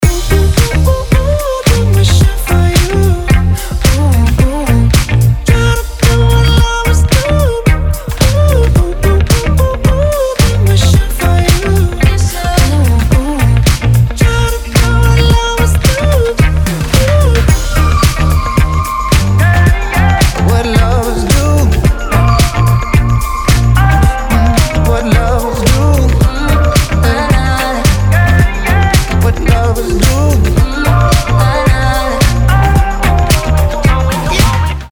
• Качество: 320, Stereo
поп
позитивные
мужской вокал
dance
Фрагмент бодрой песни про любовь